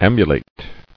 [am·bu·late]